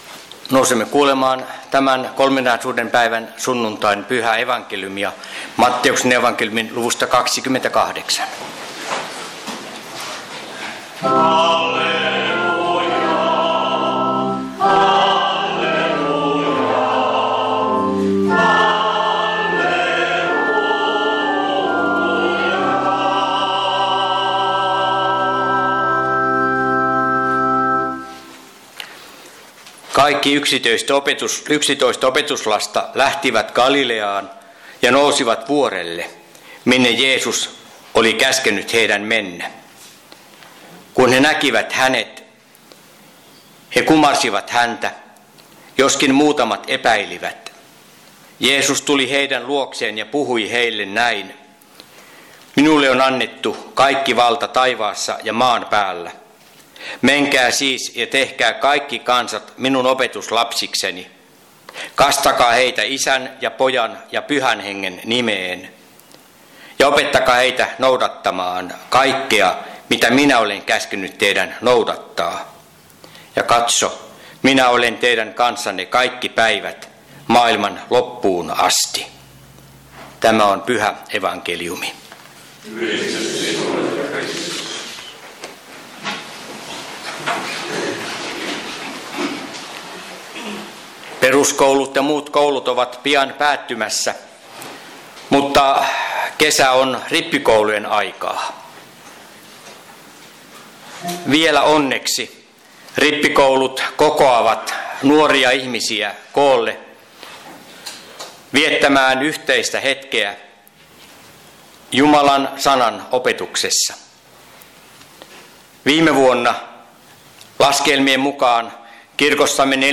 Kokoelmat: Seinäjoki Hyvän Paimenen kappelin saarnat